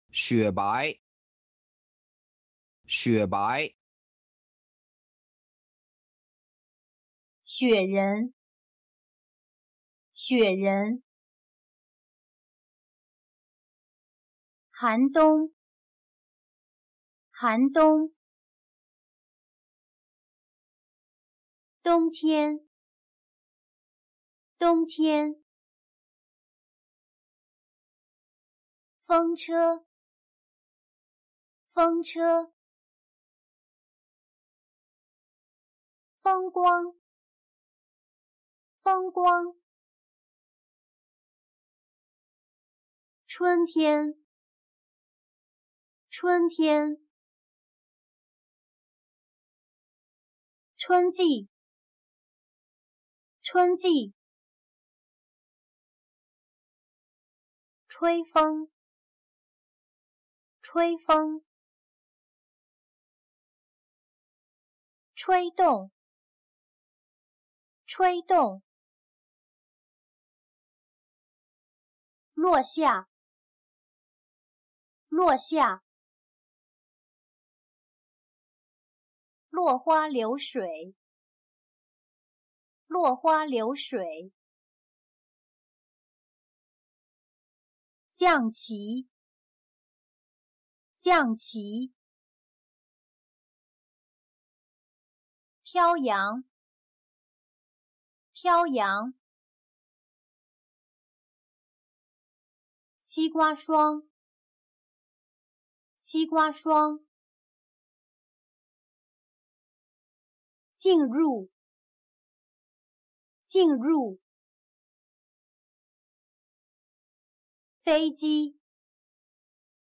AUDIO DEL DICTADO_LECCION
DICTADO.mp3